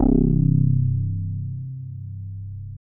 22SYN.BASS.wav